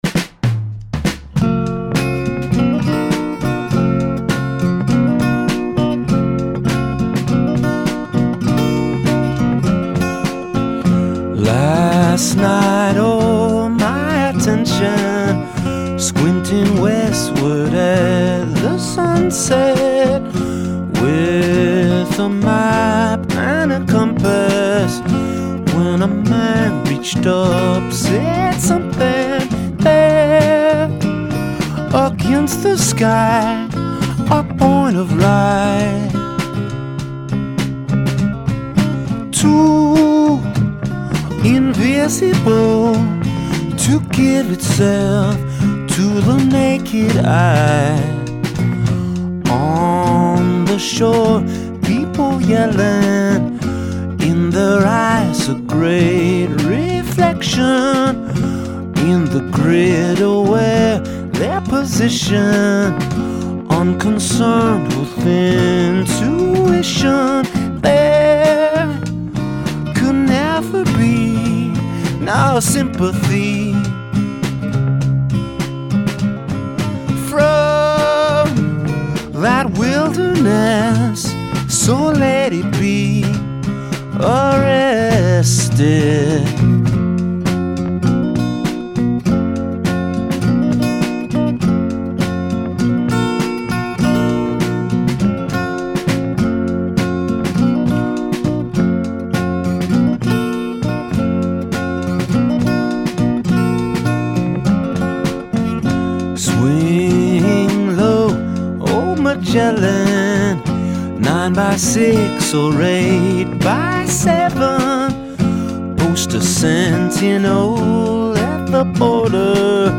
So weird but so hypnotic.
That song has a real cool retro vibe.